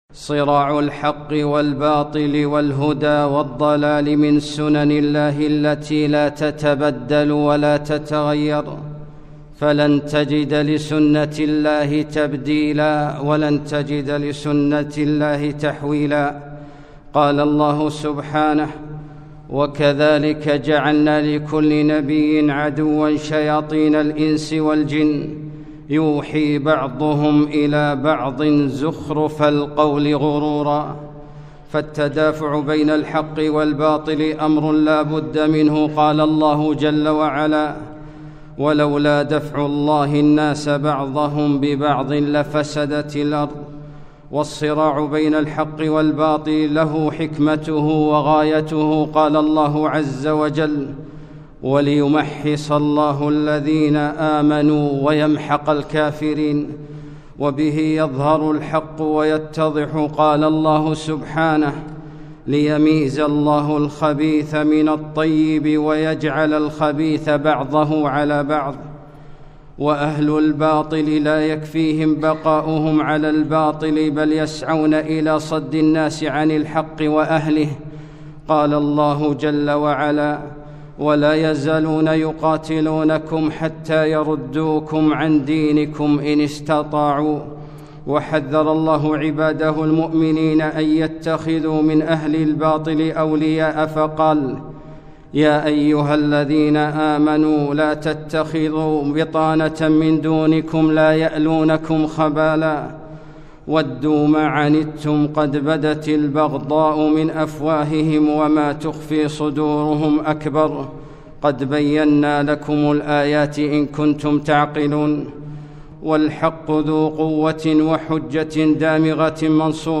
خطبة - ليميزَّ الله الخبيث من الطيب